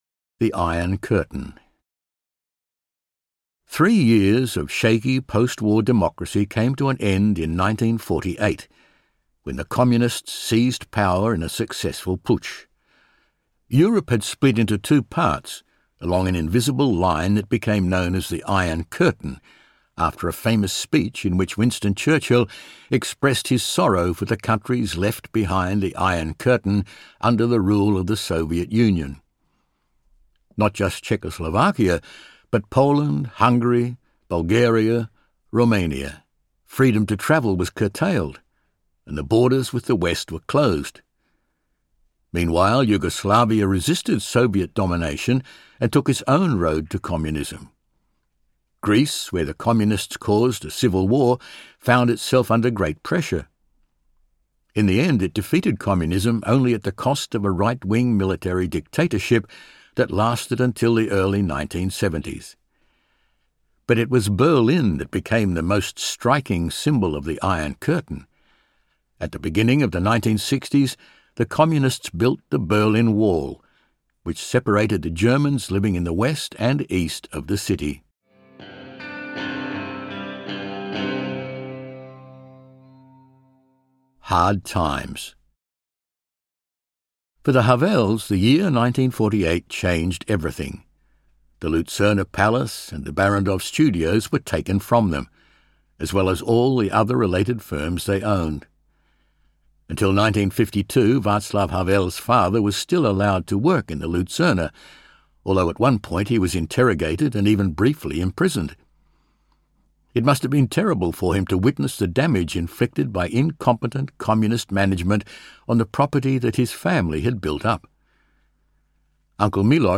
Audiobook Václav Havel – The Power of the Powerless in the 20th Century written by Martin Vopěnka.
Ukázka z knihy